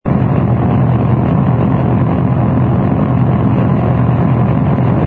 engine_rh_freighter_loop.wav